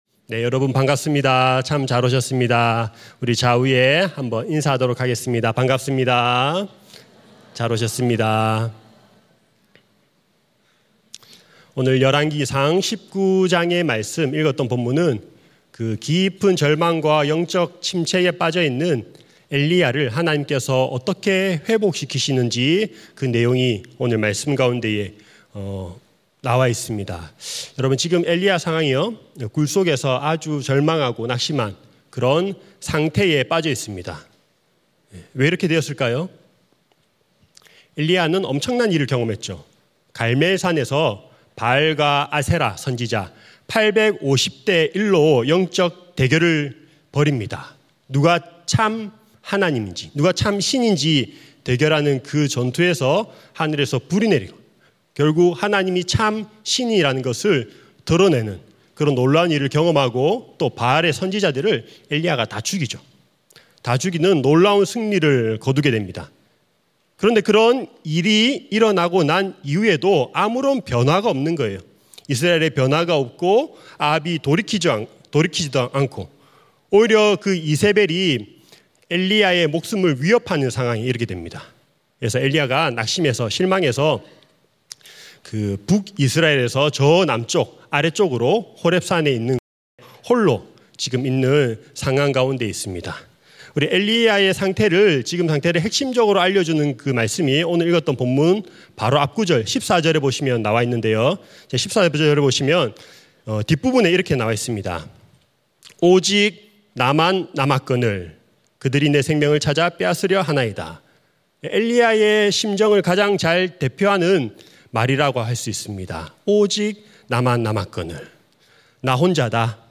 철야예배